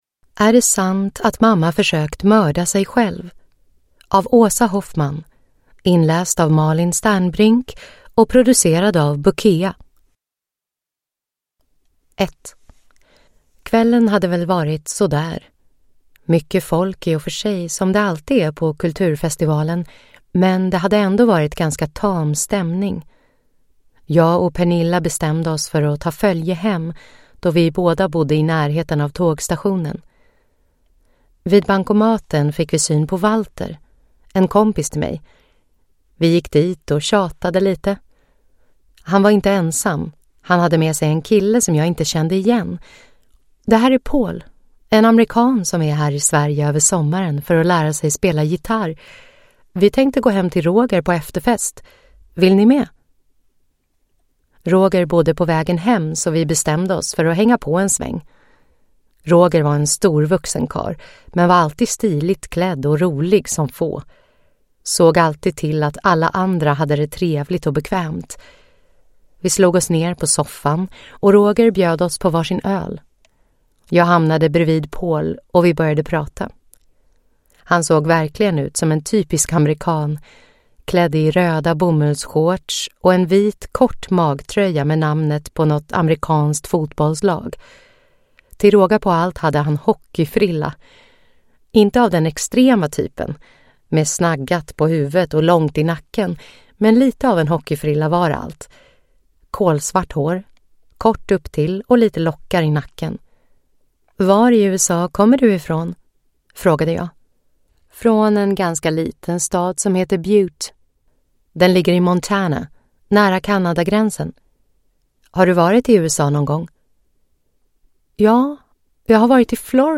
Är det sant att mamma försökt att mörda sig själv – Ljudbok